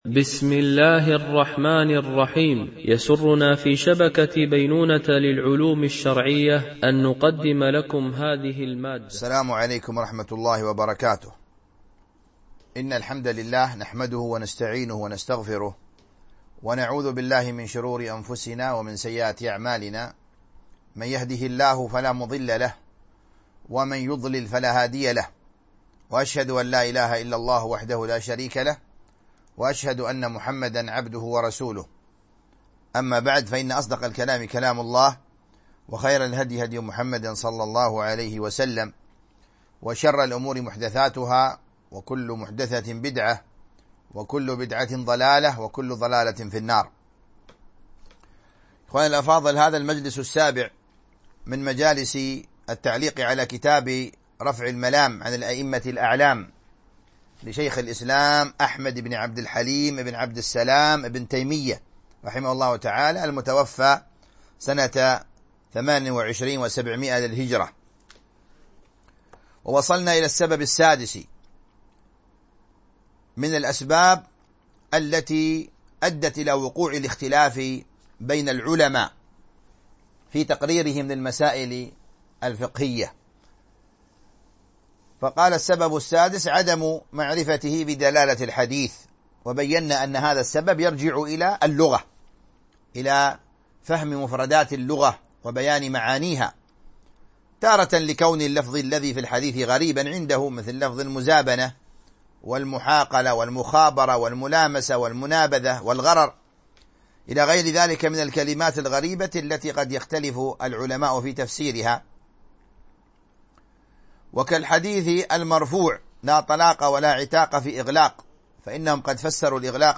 شرح كتاب رفع الملام عن الأئمة الأعلام ـ الدرس 7